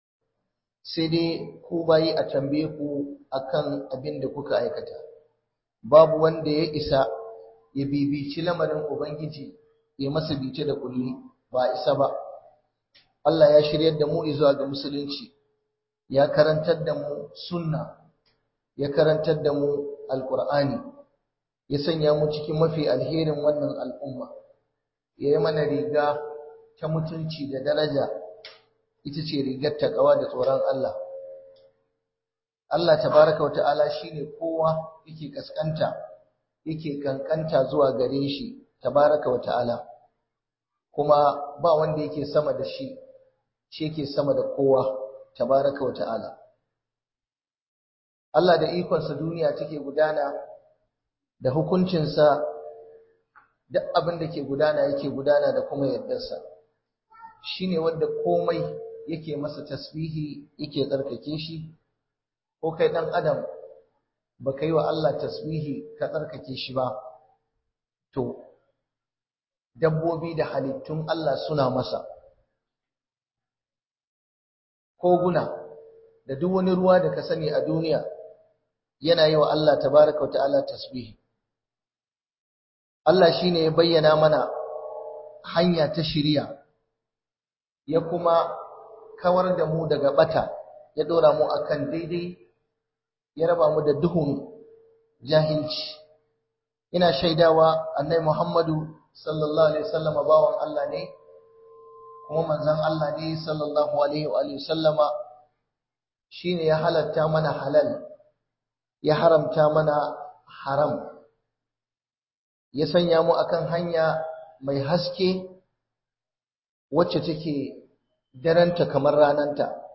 ABUBUWAN DA KE HALAKAR DA MUTANE - Huduba